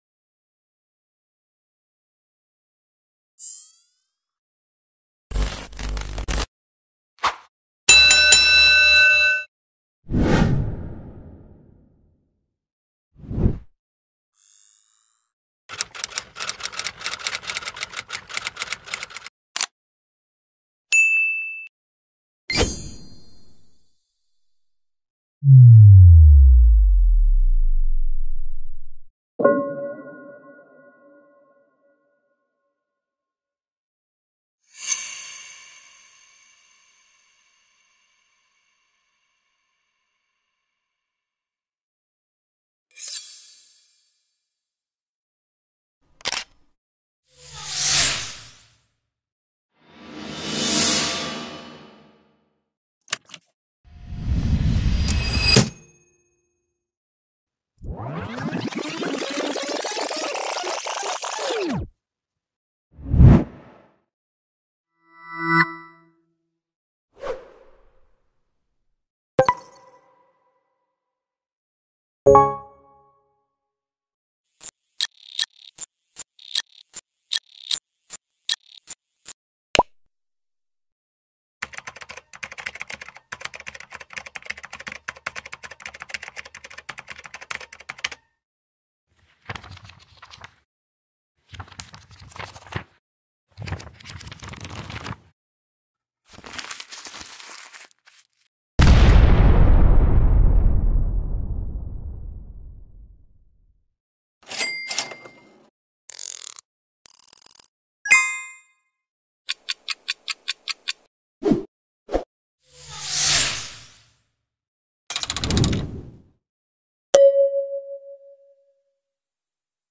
Free Sound Effects for YouTube Videos🔥 | No Copyright & High Quality
Sound effects for video editing transitions
Sound effects for video editing funny
Free_Sound_Effects_for_YouTube_Videos__No_Copyright__High_Quality.mp3